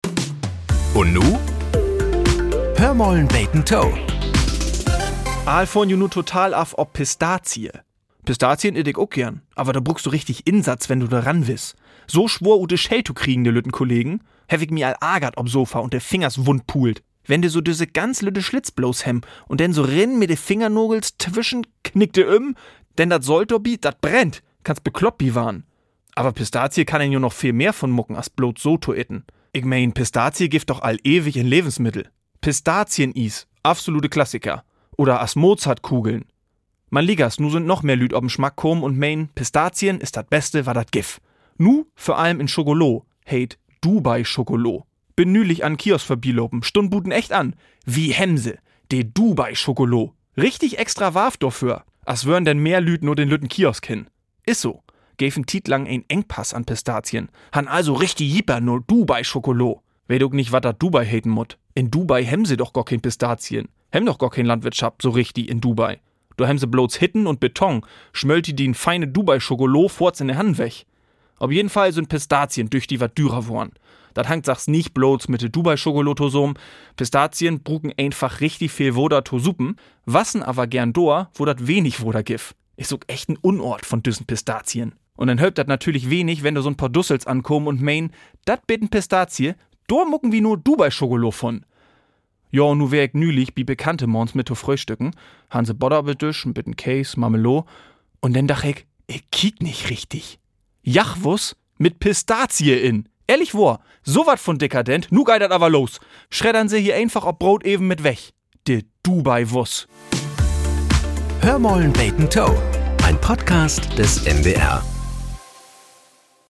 Die plattdeutsche Morgenplauderei "Hör mal 'n beten to" gehört seit mehr als 60 Jahren zum Alltag in Norddeutschland. Hier werden die Wunderlichkeiten des Alltags betrachtet.